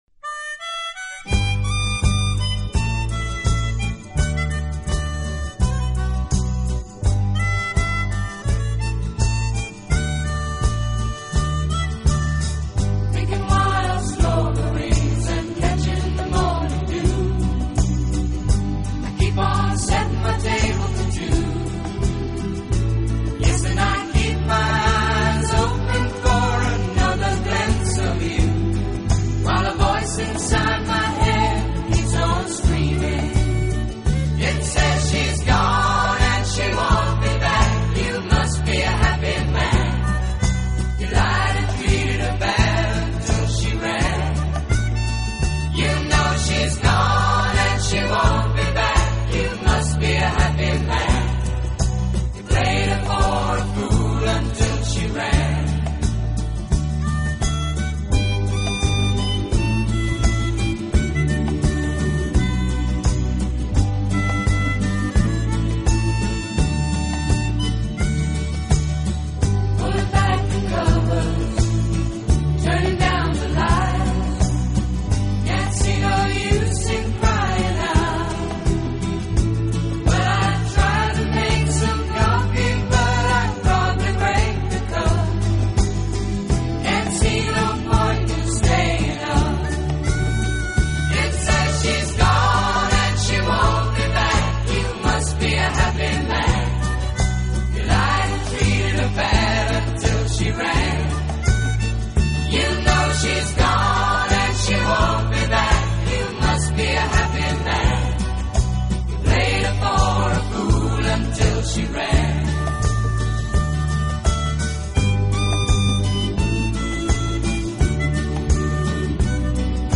视剧中的插曲等。此外，这个乐队还配置了一支训练有素，和声优美的伴唱合唱队。